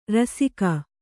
♪ rasika